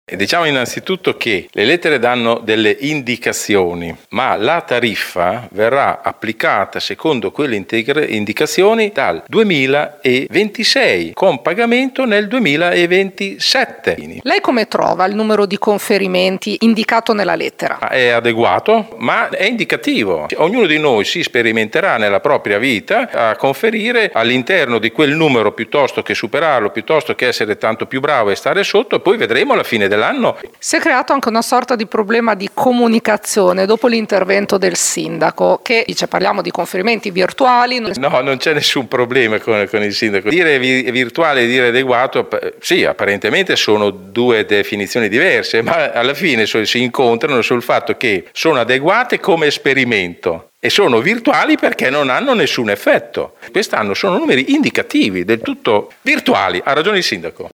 L’assessore all’ambiente Vittorio Molinari